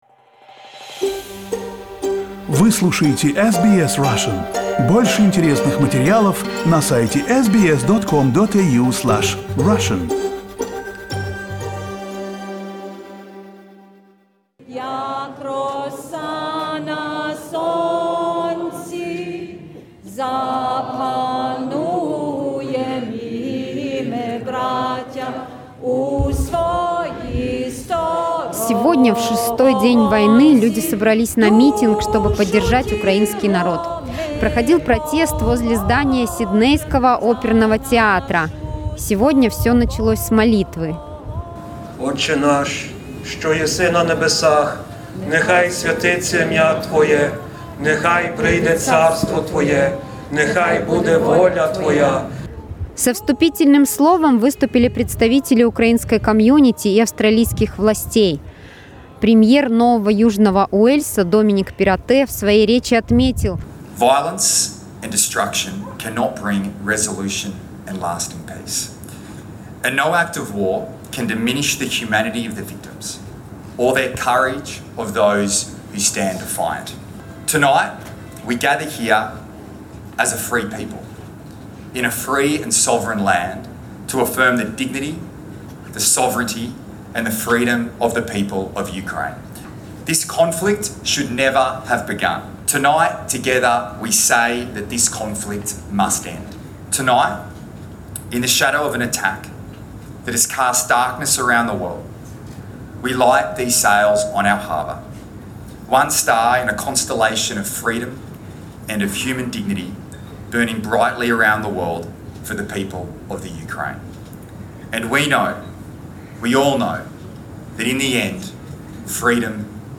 The sails of Opera House were again lit with the colours of the Ukrainian flag in a show of Australia’s solidarity. SBS Russian attended the rally yesterday and talked to people from the Russian and Ukrainian communities.